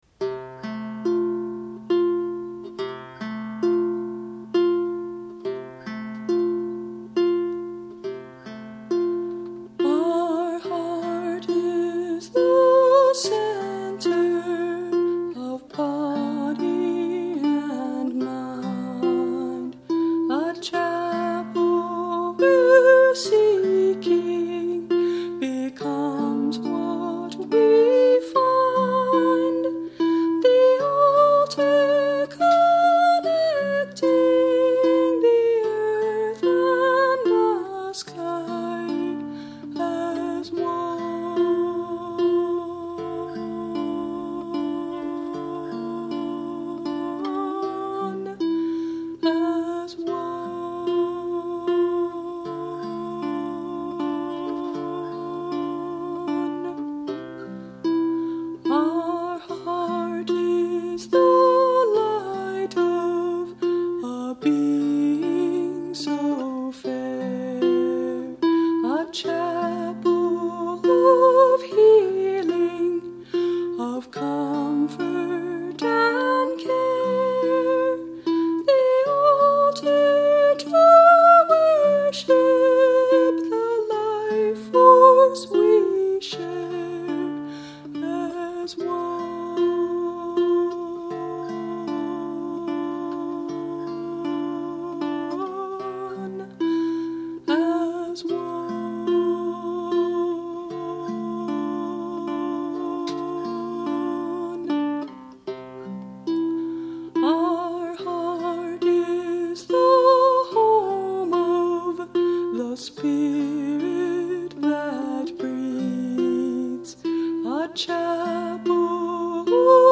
Tenor Ukulele in CGDA Tuning